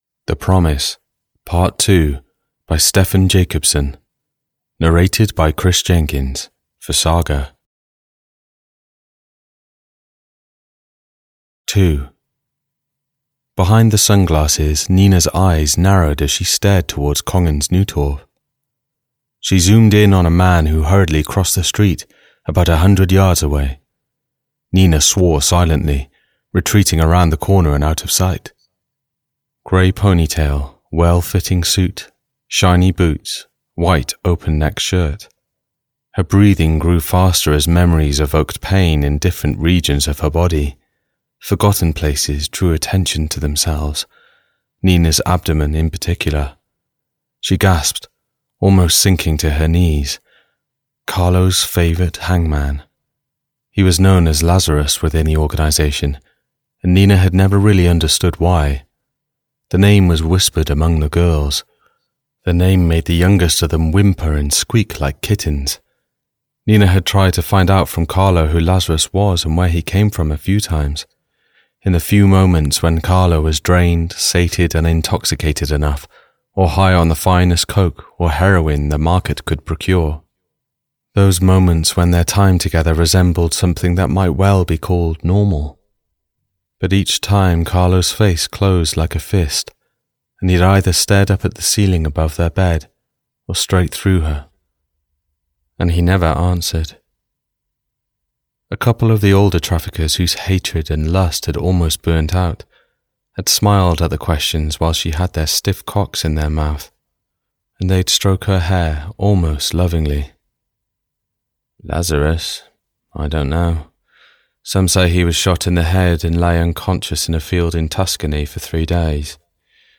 The Promise - Part 2 (EN) audiokniha
Ukázka z knihy